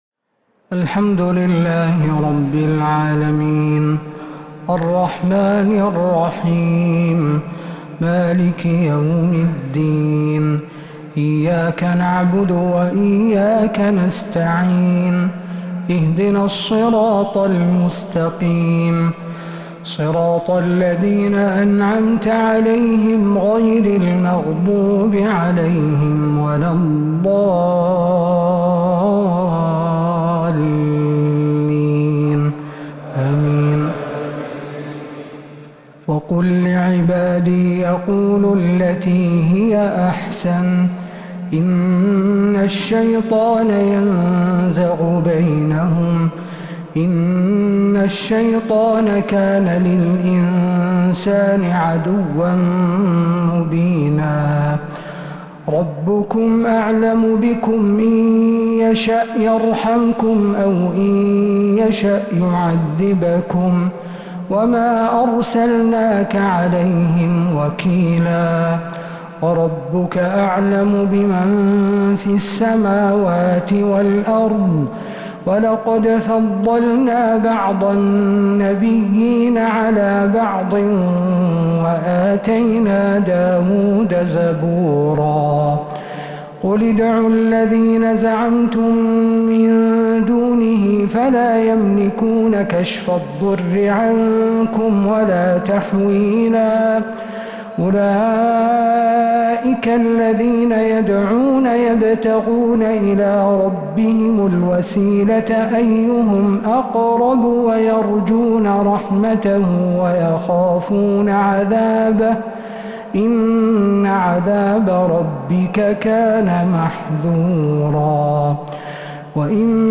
تراويح ليلة 20 رمضان 1447هـ من سورتي الإسراء (53-111) و الكهف (1-26) | Taraweeh 20th night Ramadan1447H Surah Al-Israa and Al-Kahf > تراويح الحرم النبوي عام 1447 🕌 > التراويح - تلاوات الحرمين